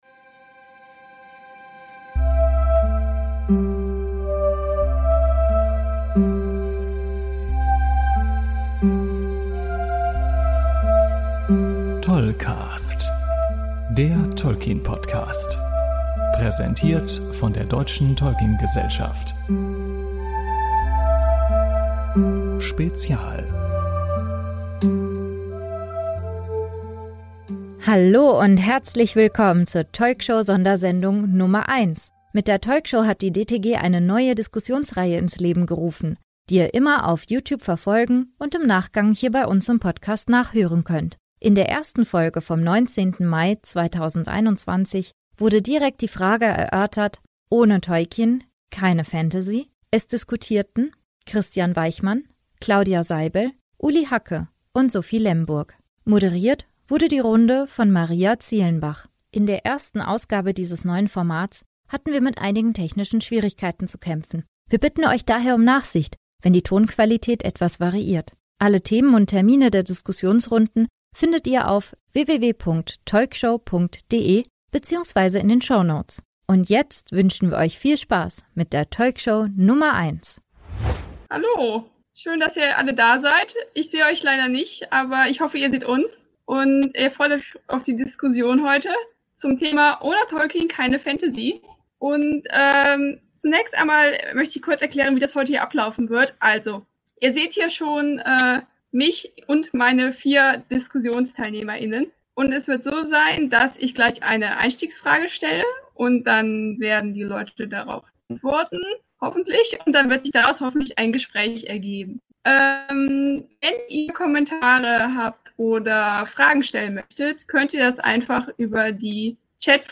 Wie hat Tolkien die moderne Fantasy geprägt? Über dieses Thema haben wir bei der ersten Ausgabe der TolkShow diskutiert. Herausgekommen ist ein bunter Streifzug durch das Fantasy und Tolkiens Werke.